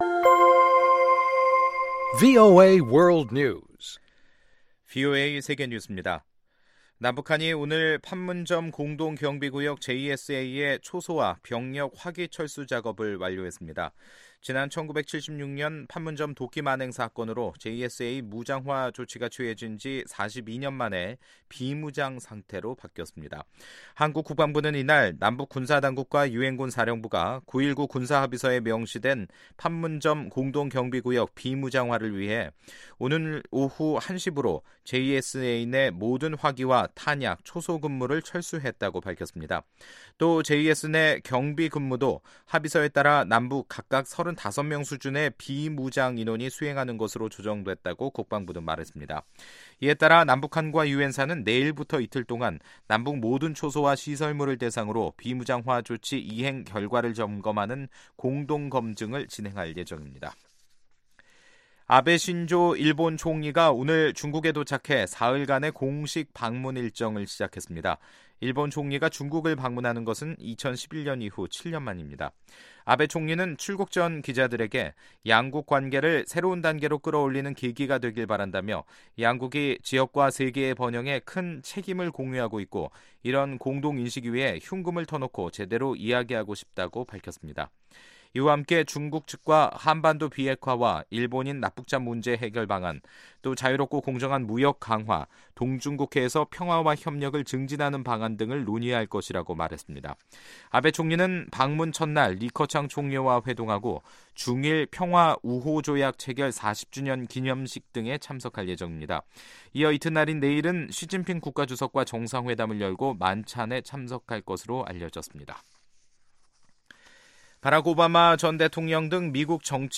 VOA 한국어 간판 뉴스 프로그램 '뉴스 투데이', 2018년 10월 25일 2부 방송입니다. 미국 국무부는 북한, 중국, 러시아가 유엔에 대북제재 완화를 촉구하는 움직임과 관련해, 이는 비핵화 이후 이뤄질 것이라는 입장을 재확인했습니다. 전 백악관 국가안보회의 (NSC) 비확산 담당 국장은 북한의 핵물질 보유량 증가를 막는 것이 중요하다며 관련 시설 폐기는 6개월에서 1년이면 기술적으로 충분하다고 말했습니다.